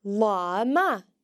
The broad L sound is made by resting your tongue behind your front teeth while pronouncing the letter, and occurs when the L is next to an a, o, or u. The broad L can be heard in latha (a day):